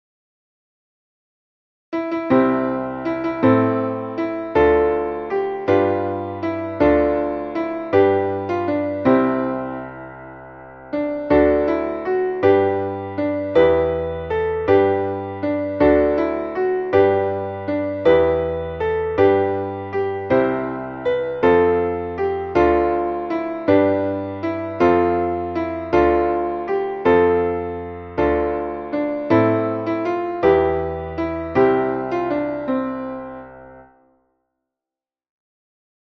Traditionelles Frühlingslied